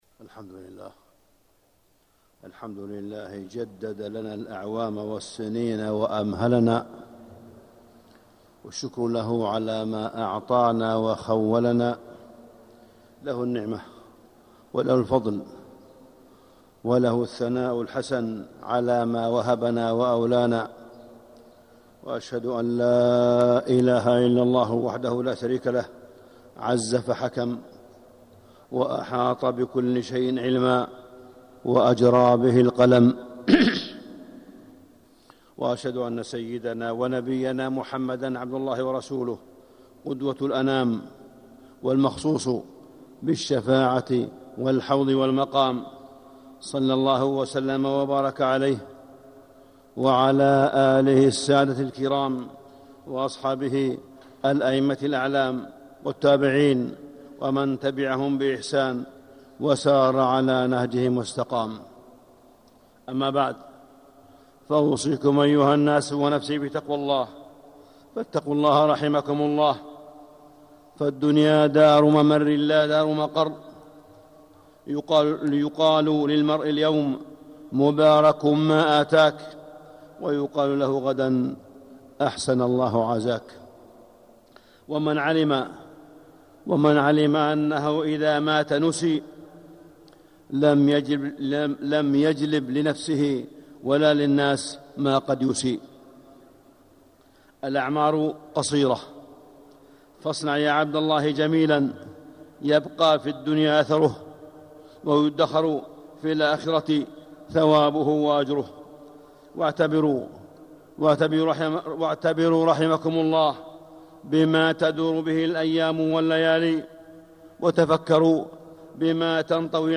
التصنيف: خطب الجمعة